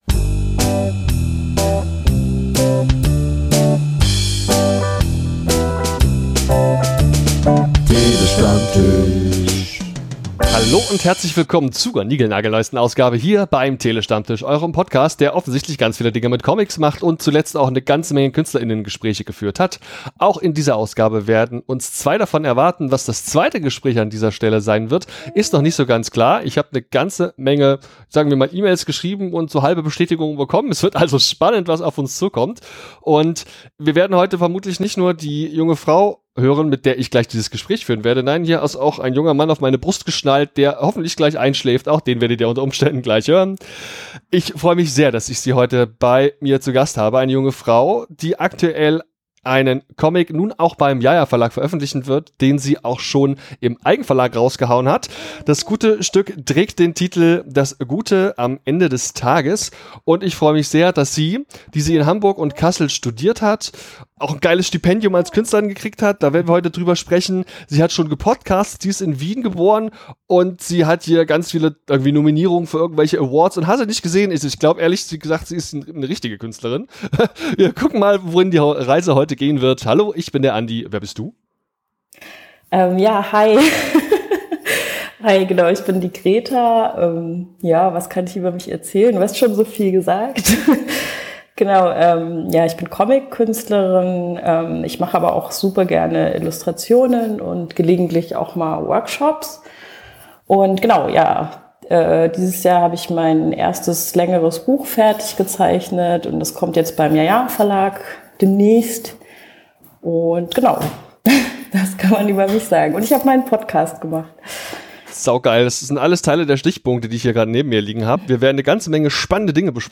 Short Crowd Cheer 2.flac